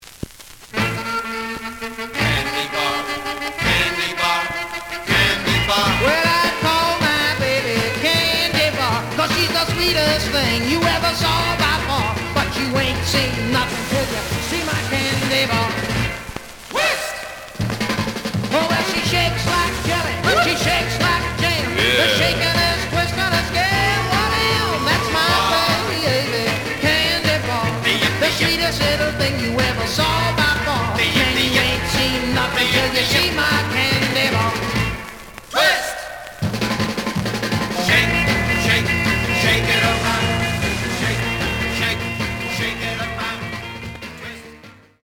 The audio sample is recorded from the actual item.
●Genre: Rhythm And Blues / Rock 'n' Roll
Some noise on parts of B side.